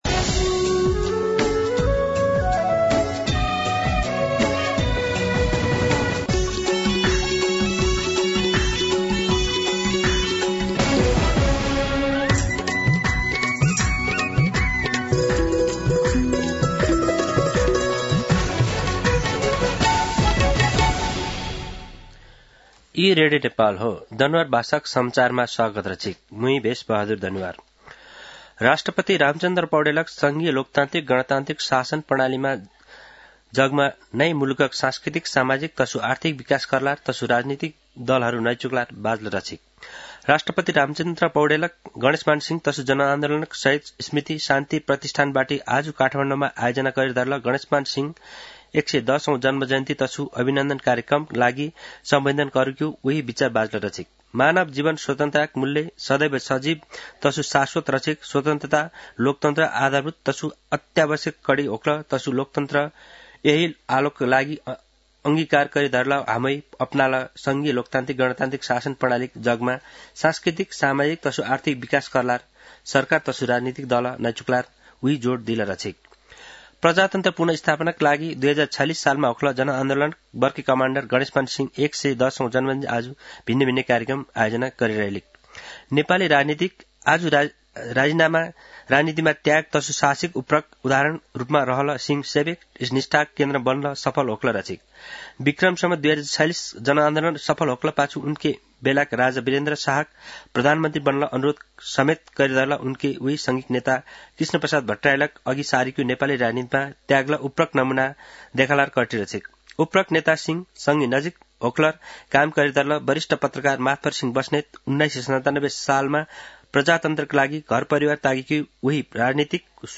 दनुवार भाषामा समाचार : २५ कार्तिक , २०८१